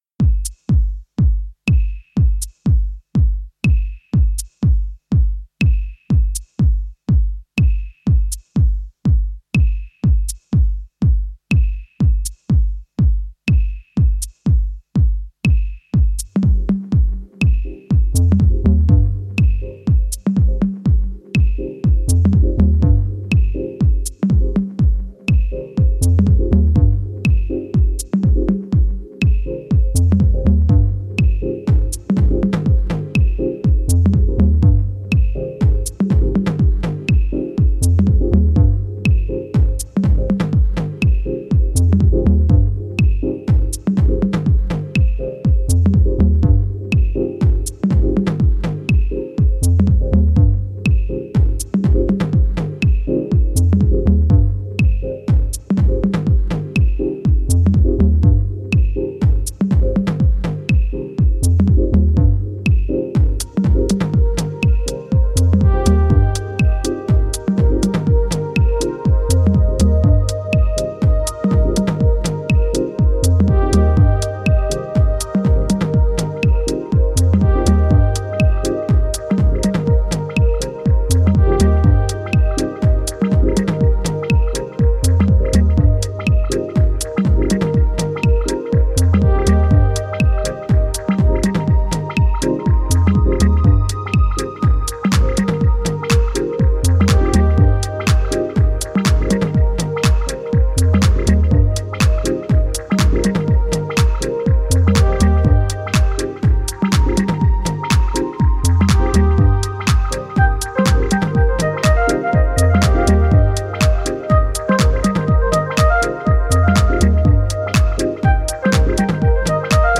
これが中々に素晴らしい内容で、アナログな質感のグルーヴや浮遊コードを駆使したディープ・ハウス群を展開しています。